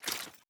Grenade Sound FX
Throw10.wav